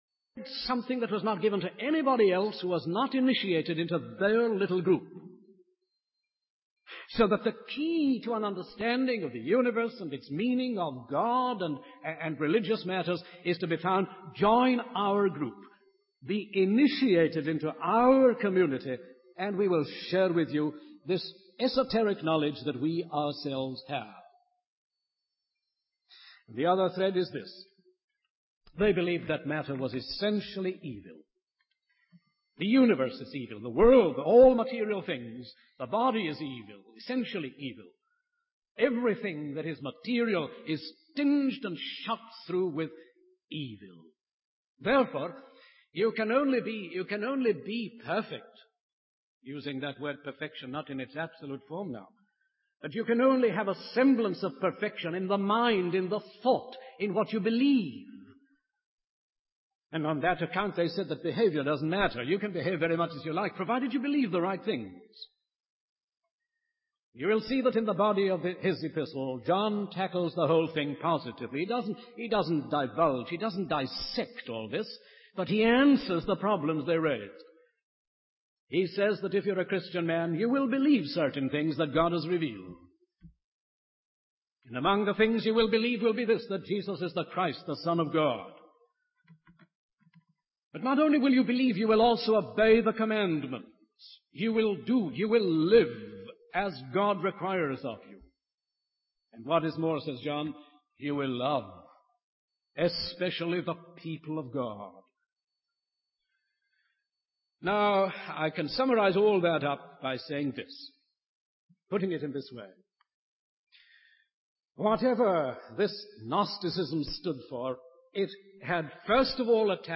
In this sermon, the speaker focuses on the foundational fact of Christianity. He reads from 1 John 1:1-2 and extracts two main thoughts from the passage.